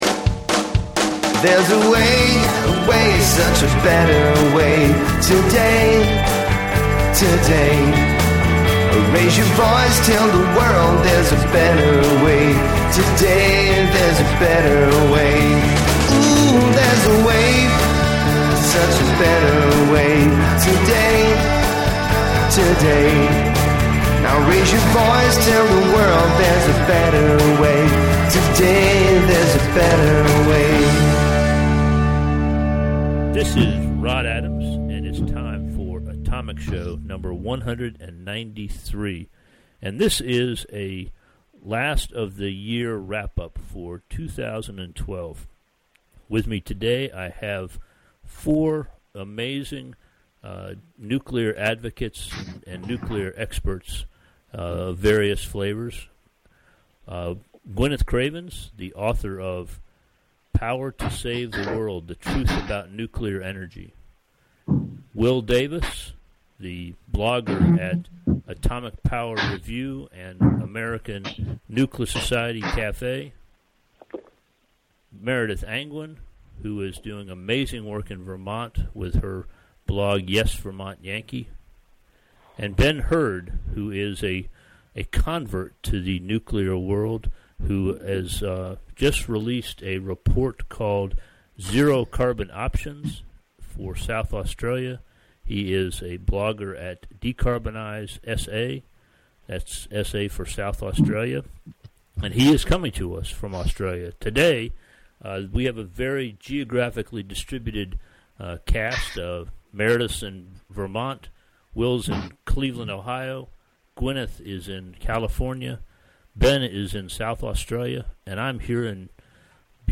On Sunday, December 30, 2012, I gathered a group of atomic advocates to talk about their favorite nuclear energy stories from 2012.